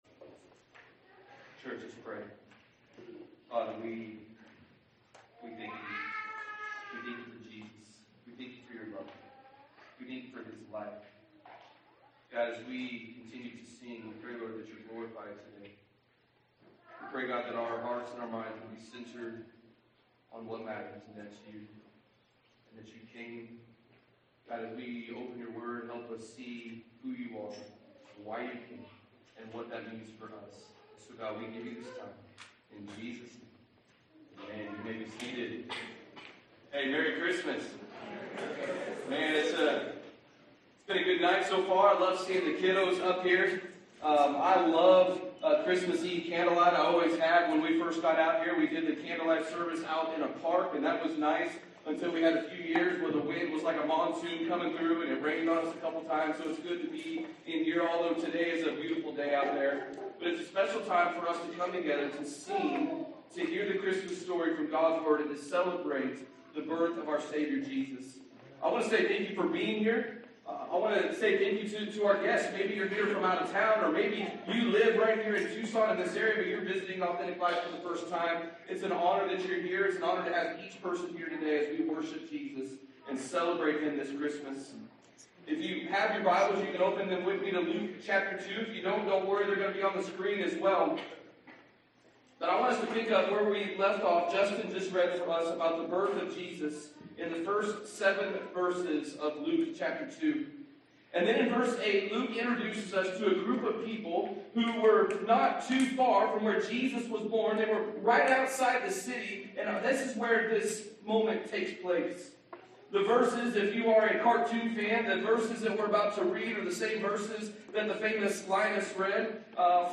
36:22 Sermon 12.8.24--Jesus